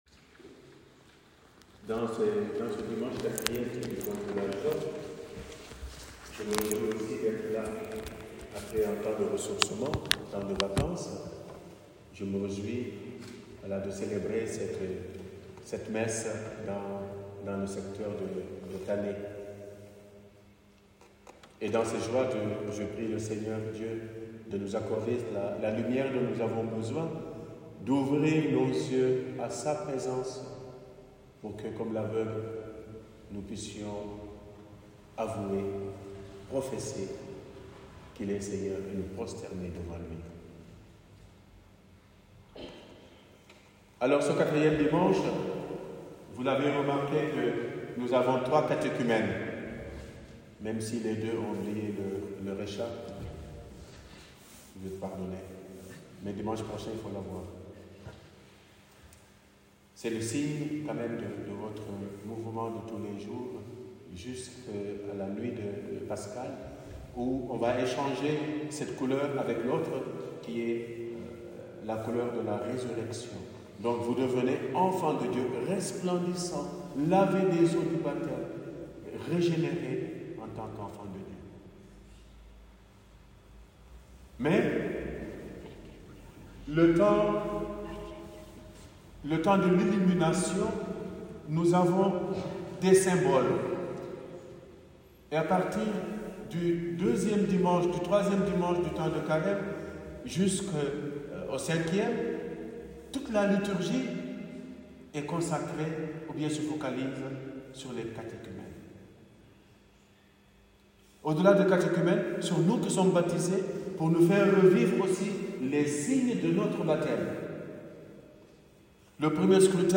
Second Scrutin pour nos 3 Catéchumènes samedi 14 mars à Tannay - Saint-François-d’Assise